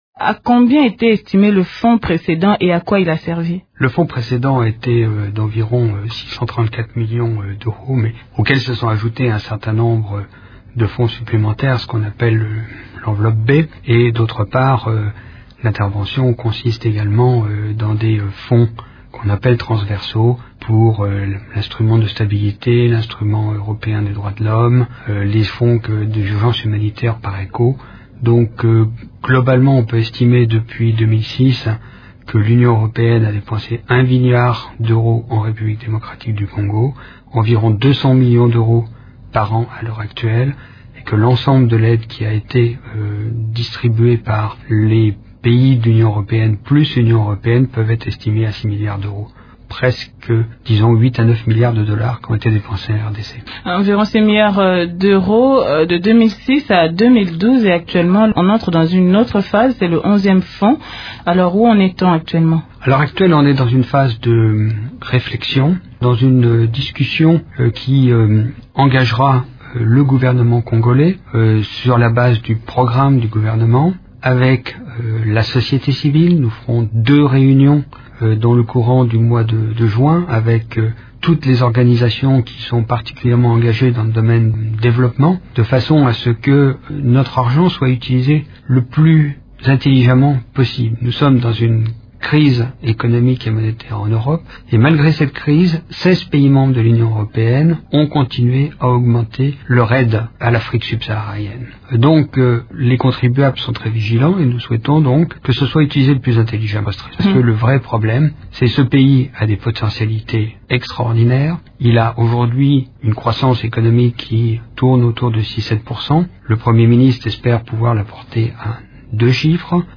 L’ambassadeur de l’Union Européenne en RDC, Jean-Michel Dumond le 8/5/2012 au studio de Radio Okapi à Kinshasa.
Jean Michel Dumond évoque les objectifs de ce fonds dans cet entretien